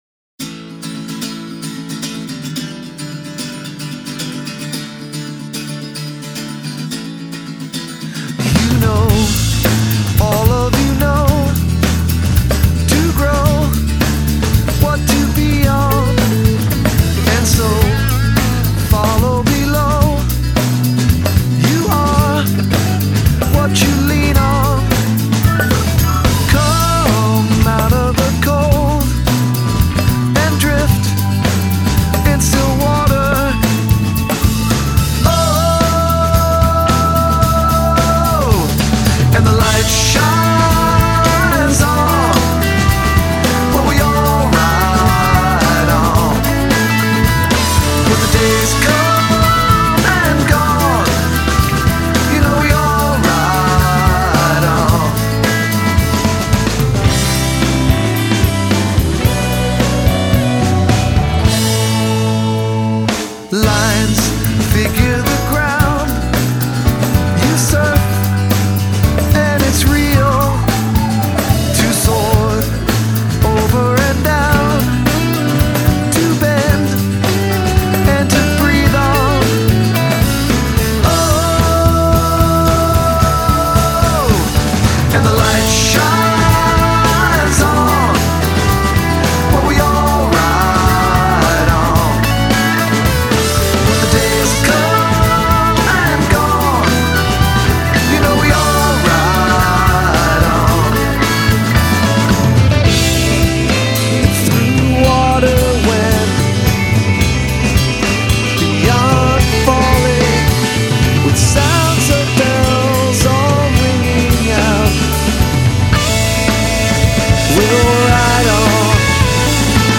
Just a jangly fun tune.